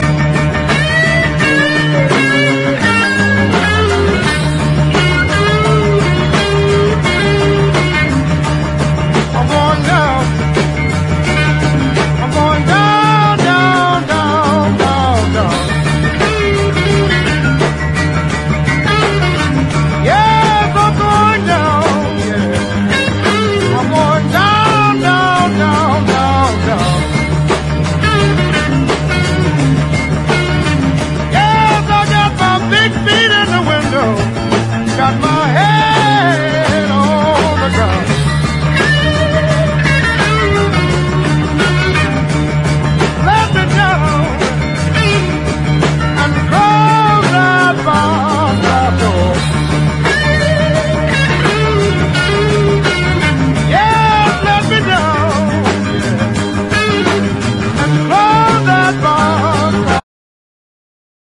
SOUL / SOUL / 70'S～